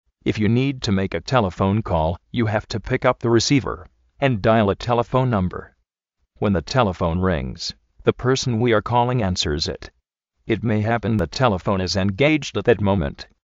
46210  four six two one o ("óu")
13722  one three seven double two ("dábl ")
télefoun kol
dáial a télefoun námber